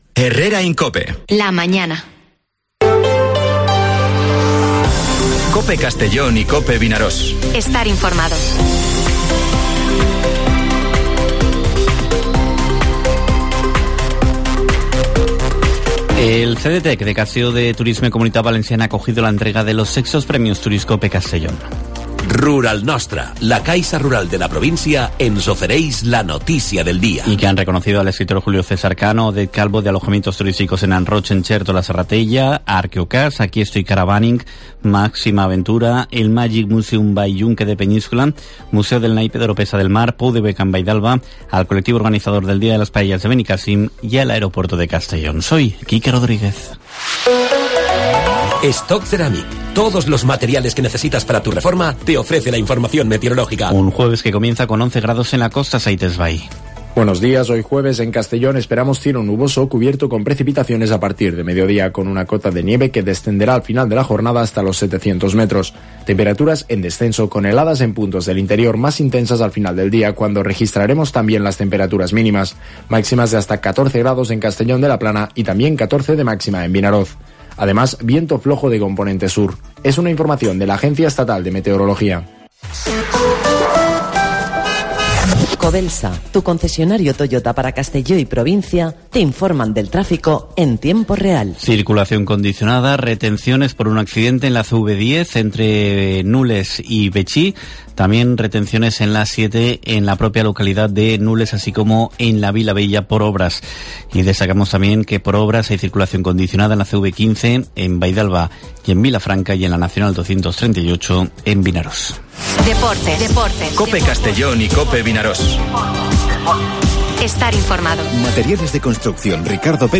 Informativo Herrera en COPE en la provincia de Castellón (23/02/2023)